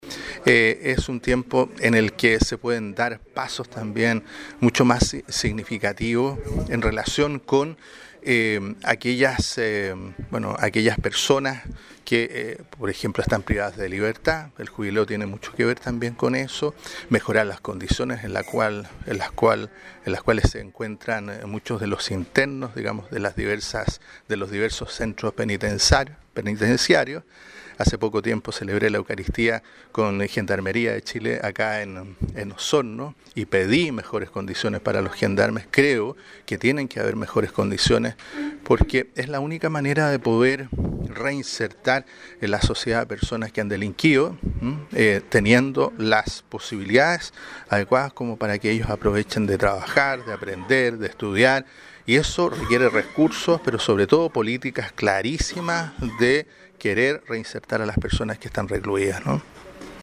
Una mañana fraterna y compartida vivieron representantes de medios de comunicación de Osorno con el obispo de la diócesis católica, monseñor Carlos Godoy Labraña.